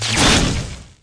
小雷击.wav